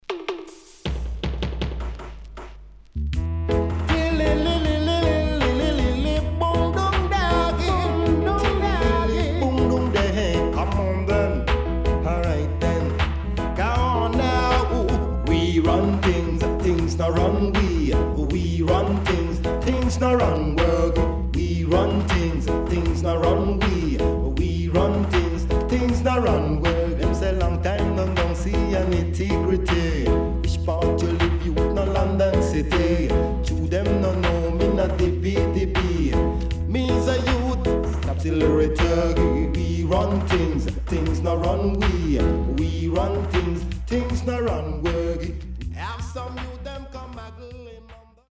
少しチリノイズ入りますが良好です。